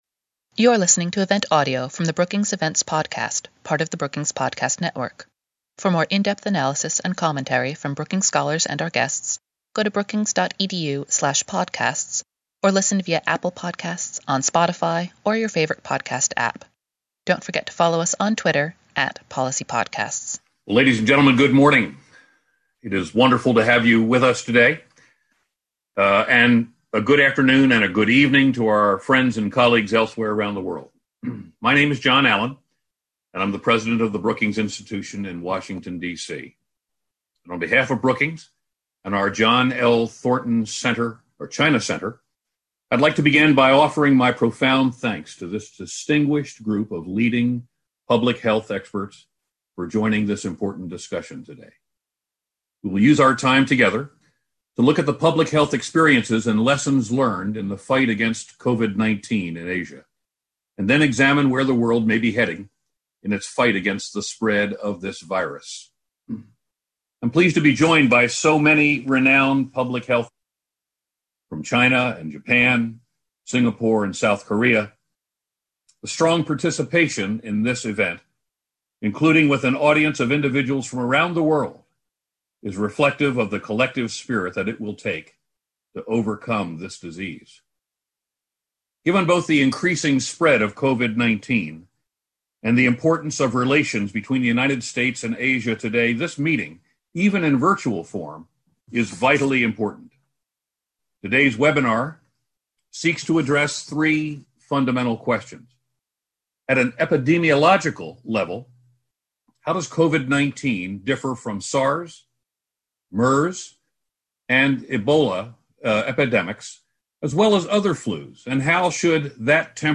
On April 3, the Brookings John L. Thornton China Center convened a remote panel of experts from China, Japan, Singapore and South Korea to discuss what has worked to combat this pandemic.